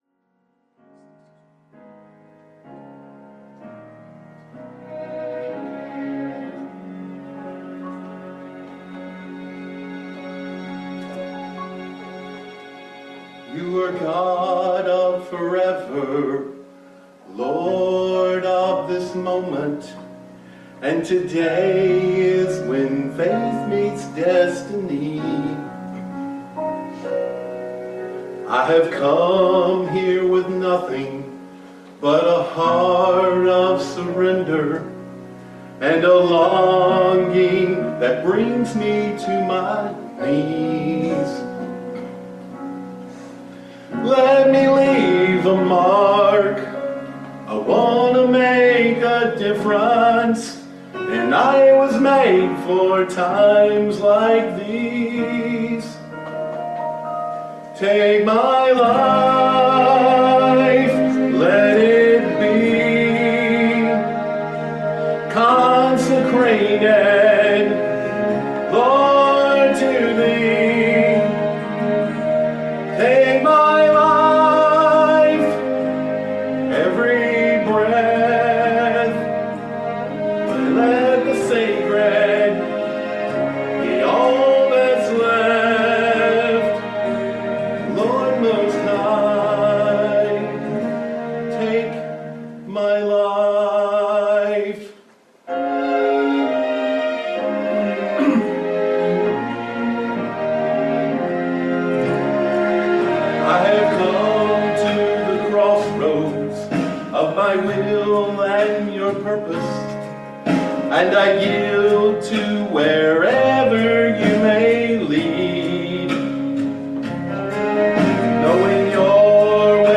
Psalms 33:1-3 Service Type: Family Bible Hour Our new song should be filled with the glories of God